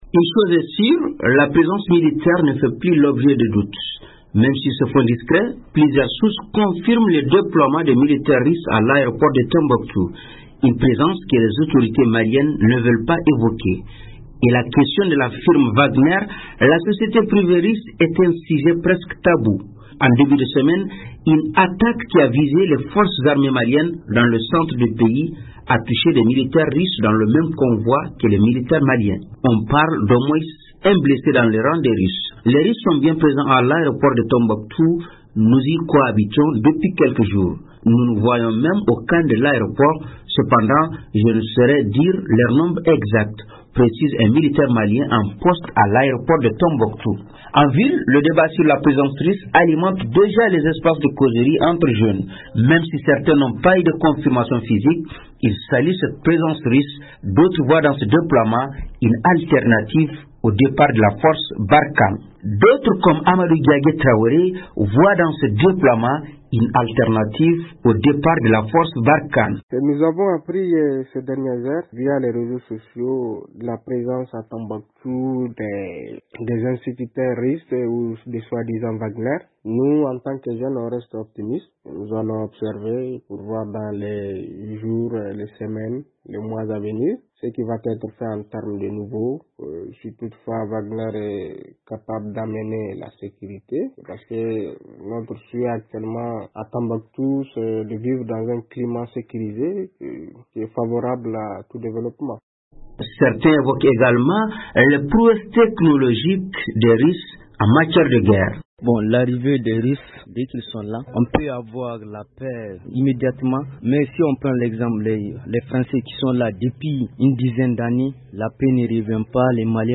Depuis Tombouctou la correspondance